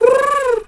Animal_Impersonations
pigeon_2_emote_01.wav